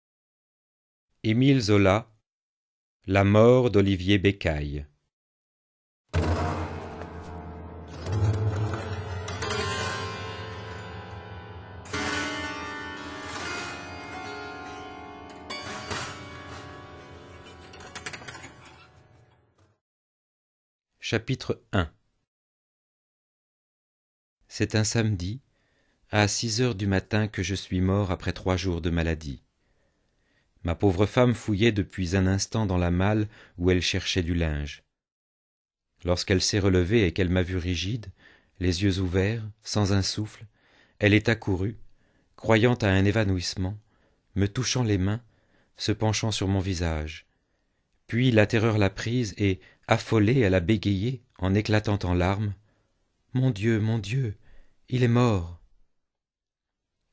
Diffusion distribution ebook et livre audio - Catalogue livres numériques
Paul Cézanne Musique : Erik Satie 7